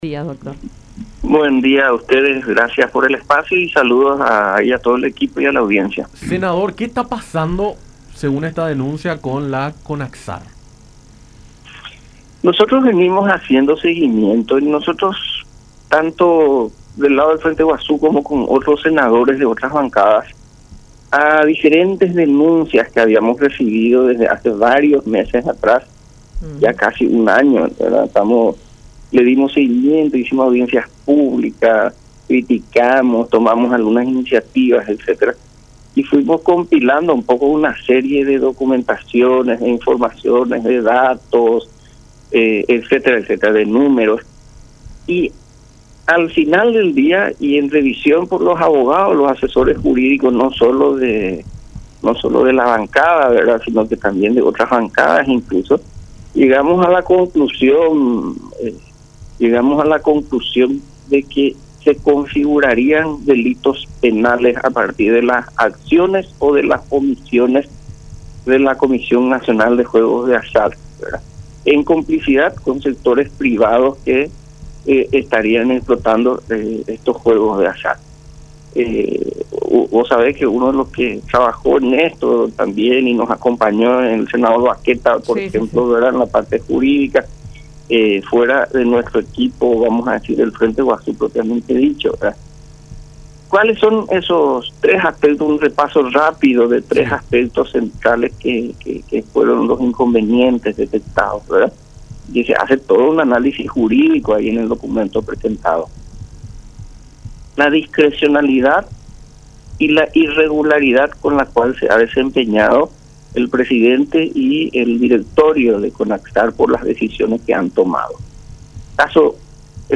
Jorge Querey, senador del Frente Guasu, dio detalles sobre las denuncias recogidas e investigadas acerca de presuntas irregularidades vinculadas a acciones y omisiones de la Comisión Nacional de Juegos de Azar (CONAJZAR).
explicó Querey en diálogo con Enfoque 800 por La Unión.